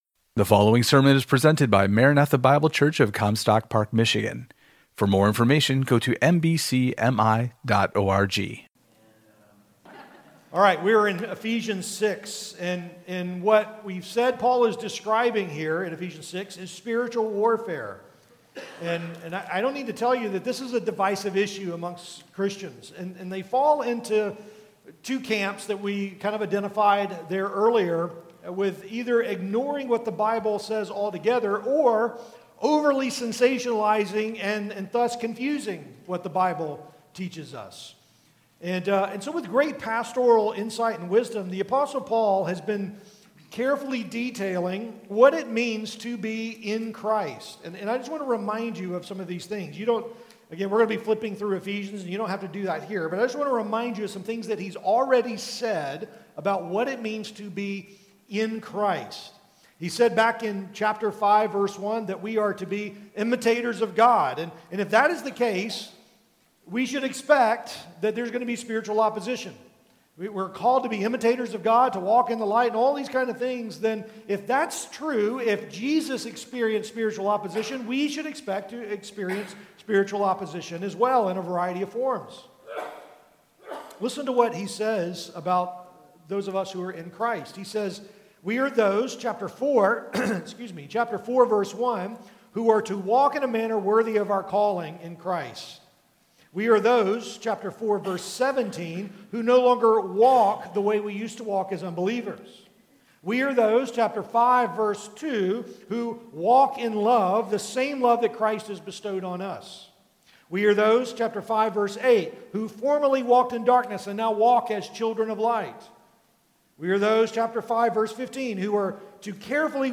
Equipping the Saints Conferences